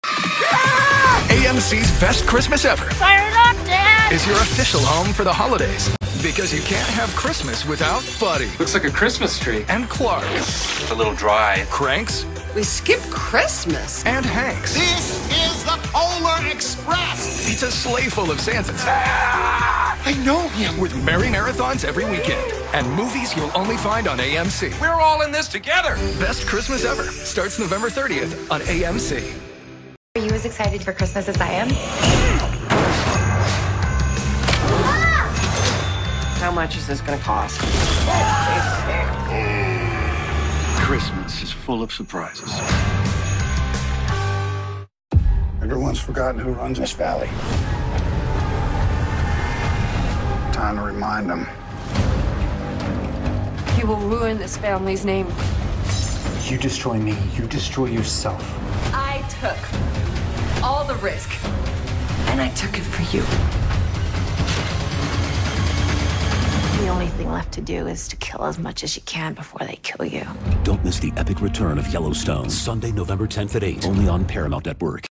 Promos Download This Spot